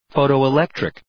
Προφορά
{,fəʋtəʋı’lektrık}
photoelectric.mp3